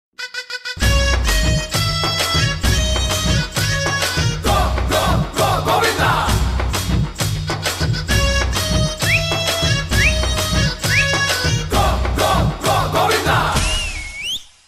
File Type : Latest bollywood ringtone